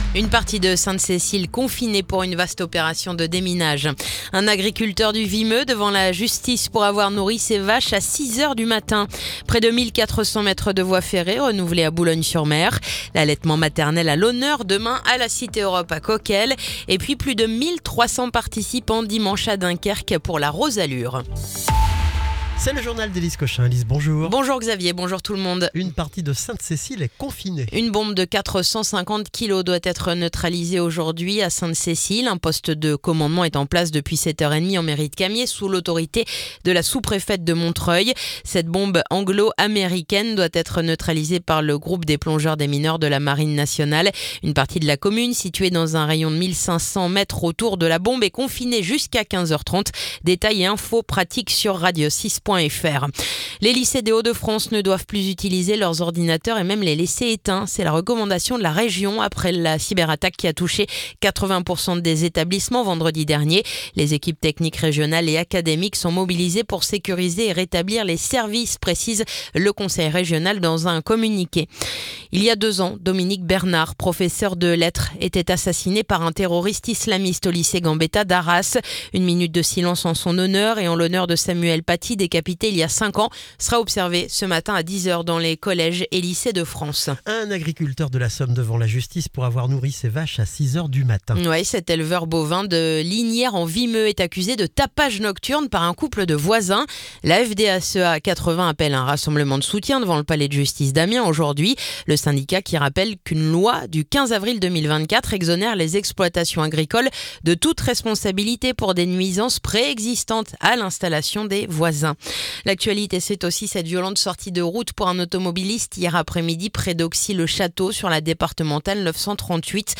Le journal du mardi 14 octobre